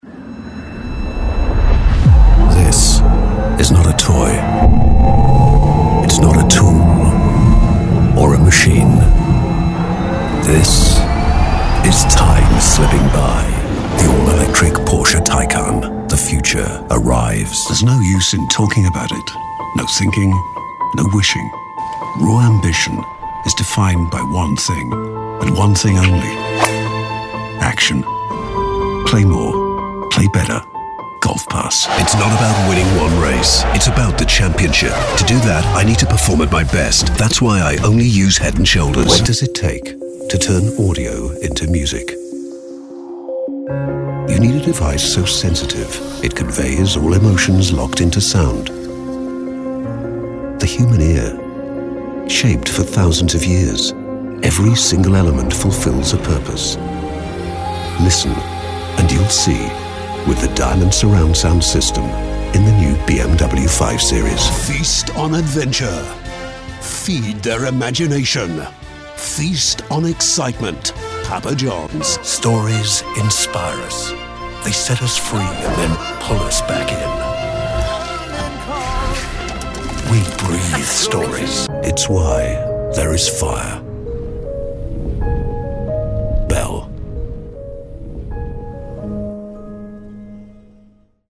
Englisch (Britisch)
Hallo, ich bin ein britischer Schauspieler und Synchronsprecher mit umfassender Erfahrung und einem internationalen Kundenstamm.
Vertrauenswürdig
Warm
Autorisierend